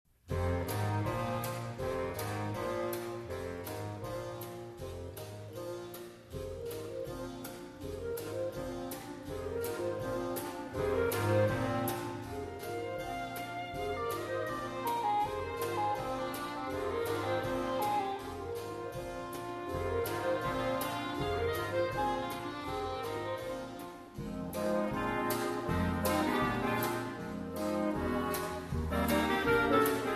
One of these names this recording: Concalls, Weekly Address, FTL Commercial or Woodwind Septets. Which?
Woodwind Septets